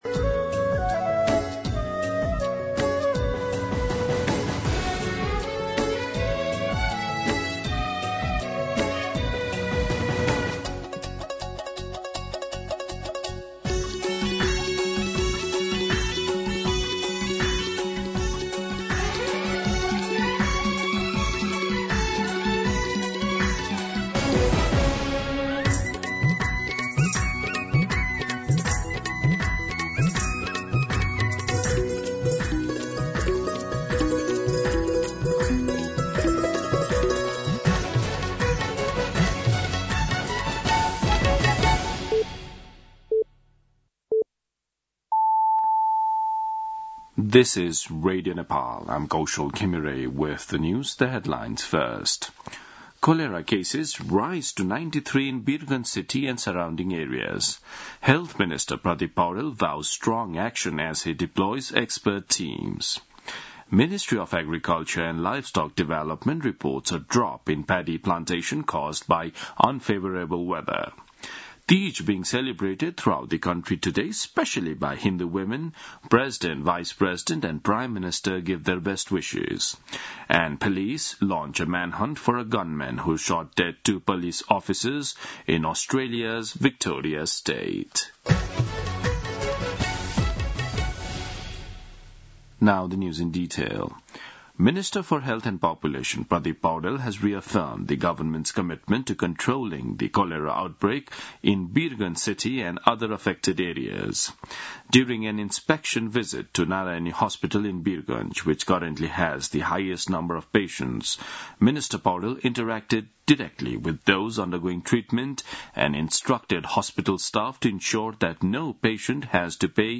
दिउँसो २ बजेको अङ्ग्रेजी समाचार : १० भदौ , २०८२